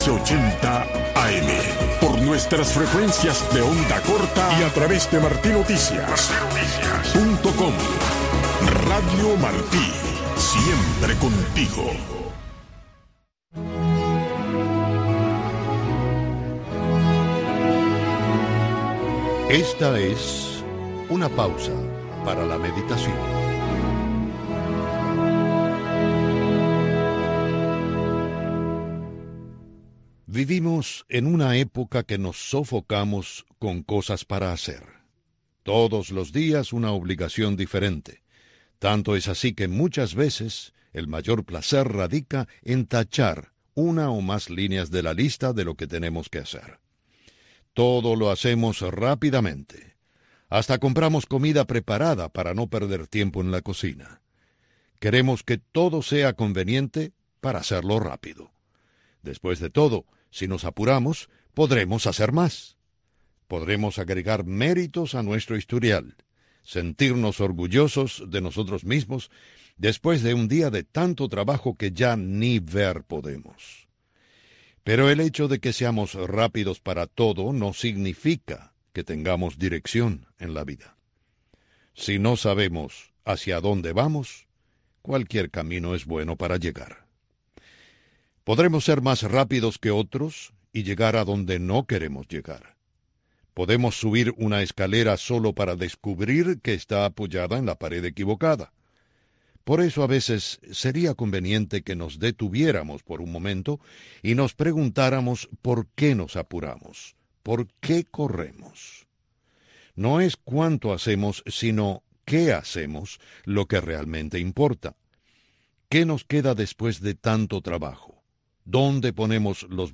Revista informativa con noticias, eventos, blogs cubanos, efemérides, música y un resumen de lo más importante de la semana en el mundo del arte.